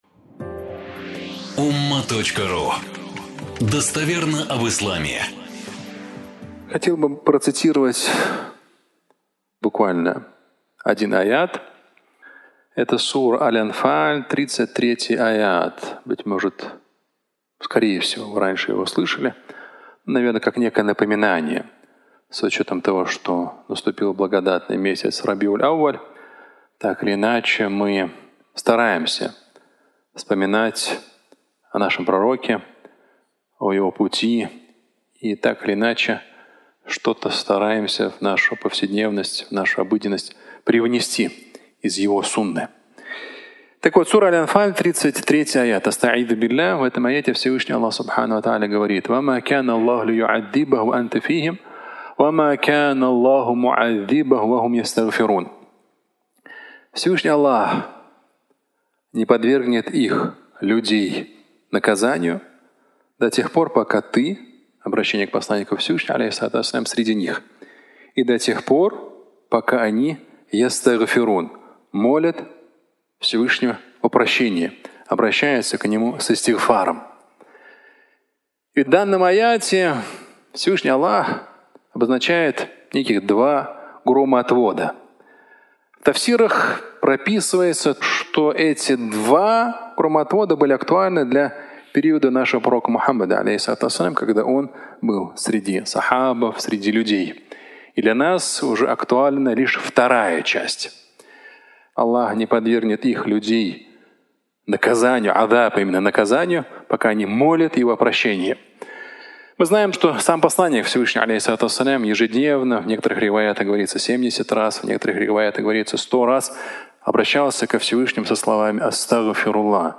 Один ответ на все вопросы (аудиолекция)